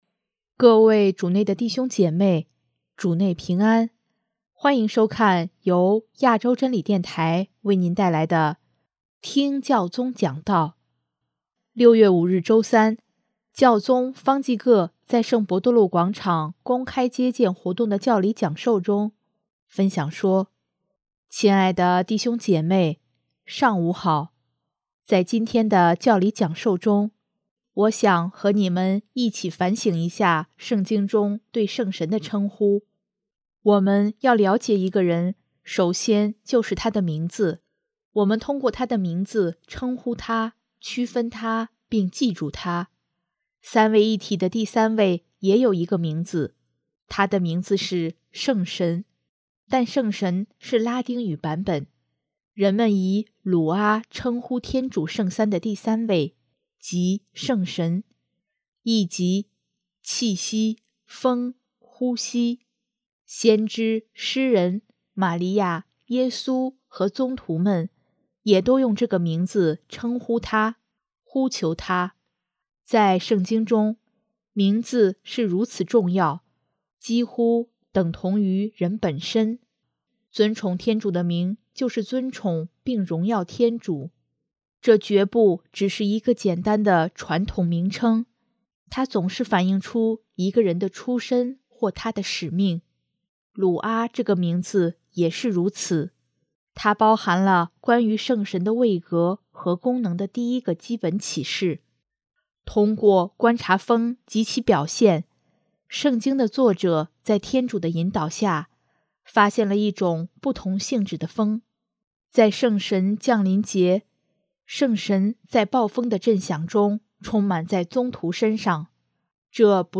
【听教宗讲道】|圣神是不可控制的风，使人自由
6月5日周三，教宗方济各在圣伯多禄广场公开接见活动的教理讲授中，分享说：